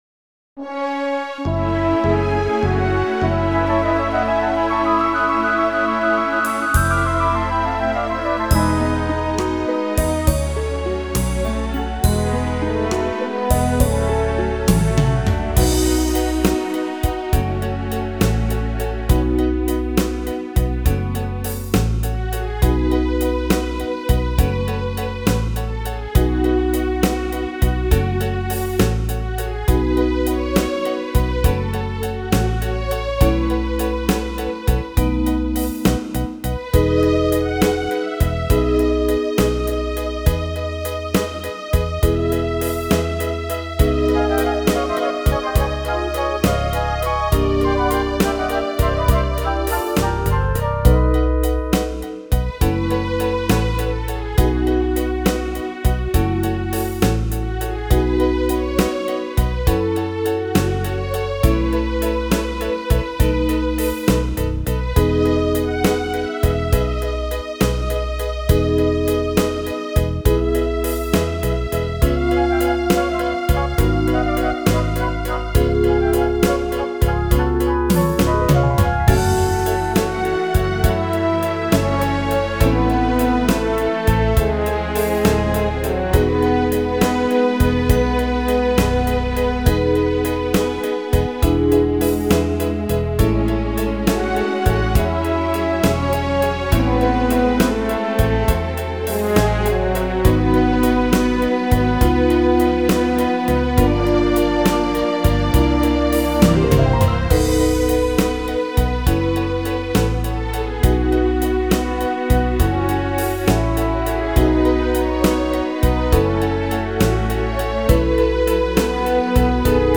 untitled  Download Instrumental
This jazz standard has quite the history.